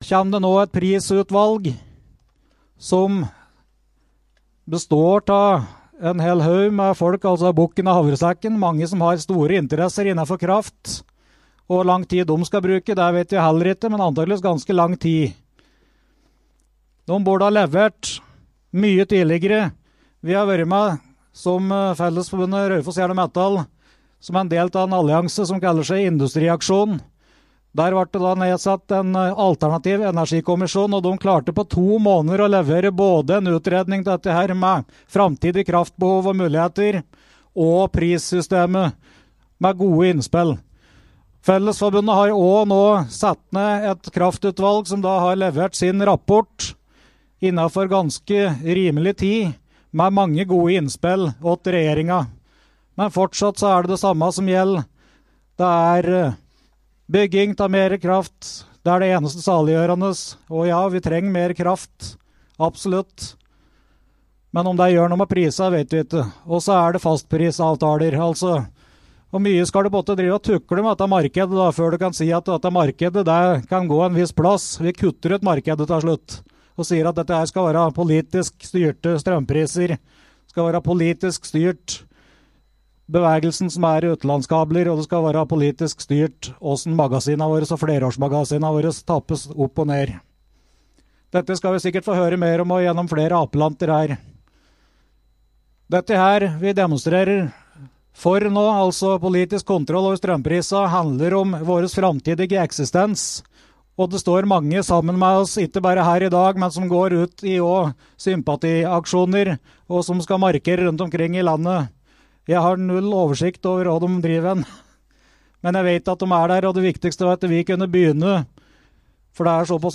Streiktoget gikk til Nytorget der det ble holdt en rekke apeller.